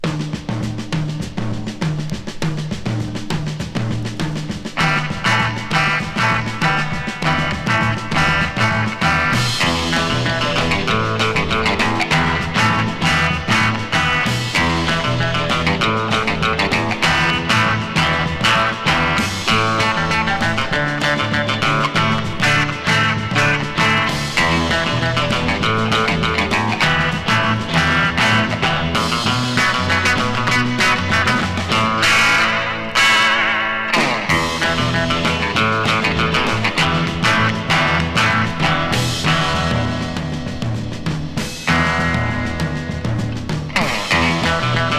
Rock'N'Roll, Surf　USA　12inchレコード　33rpm　Stereo